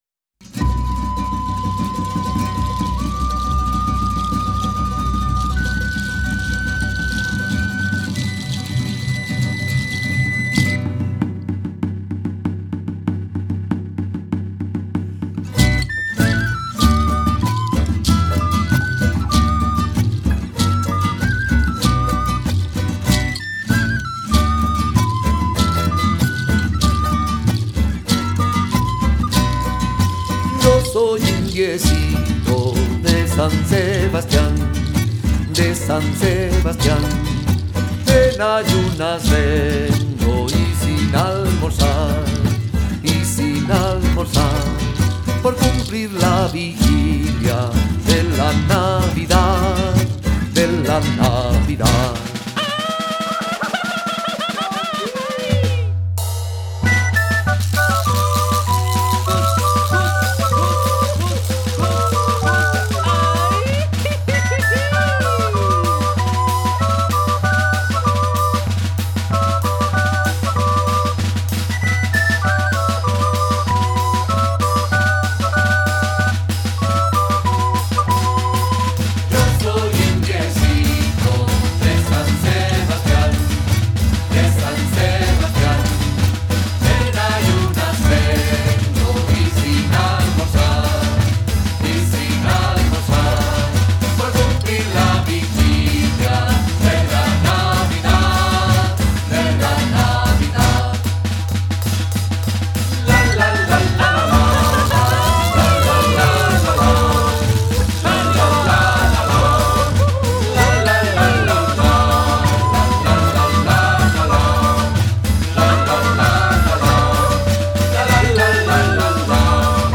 Interpretación musical
Canto
Música tradicional